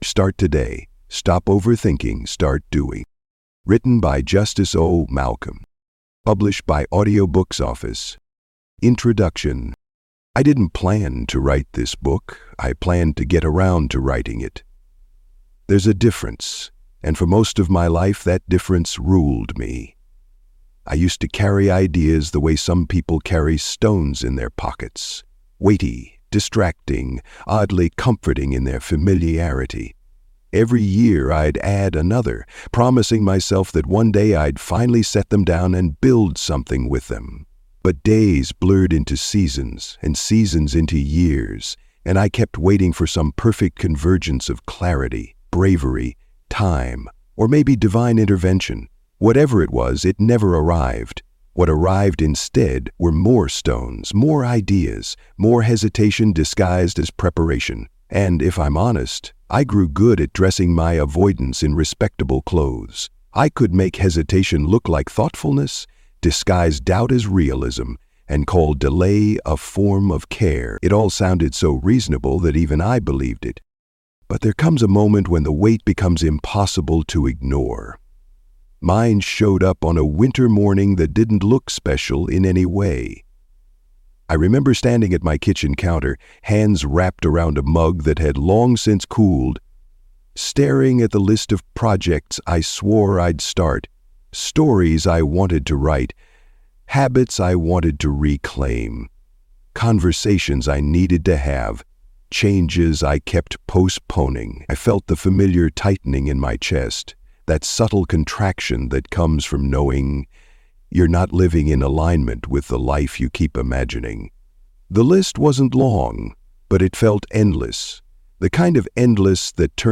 Be Your Own Hero: Save Yourself From Self-Sabotage (Audiobook)